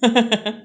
haha_4.wav